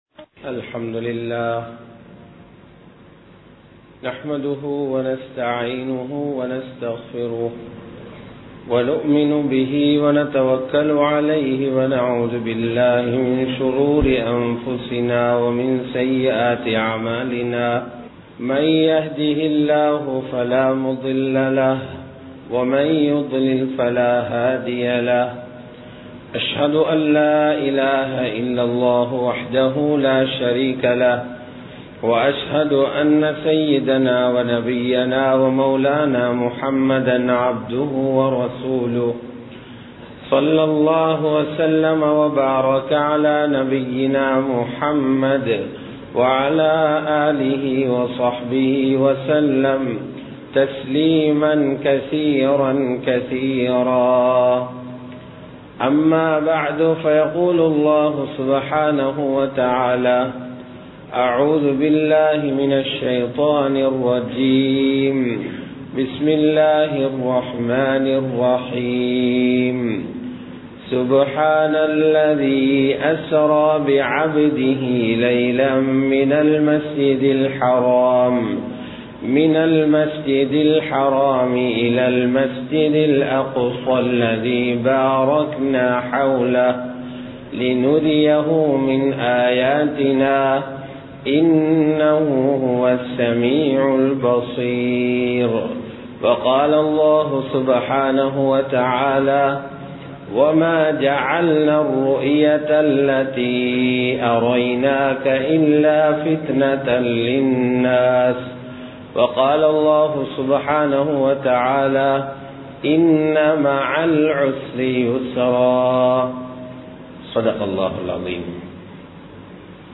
மிஃறாஜ் கூறும் பாடம் | Audio Bayans | All Ceylon Muslim Youth Community | Addalaichenai
Colombo 03, Kollupitty Jumua Masjith